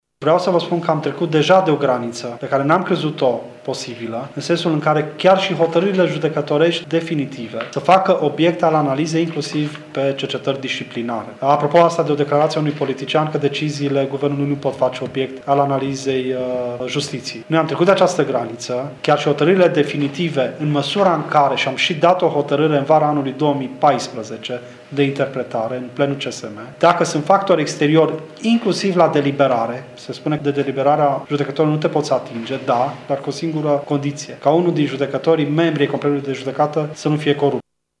Membrul Consiliului Superior al Magistraturii, judecătorul Horaţius Dumbravă, a declarat astăzi că în ultima vreme s-au înmulţit atât condamnările magistraţilor acuzaţi de acte de corupţie dar şi sancţiunile disciplinare.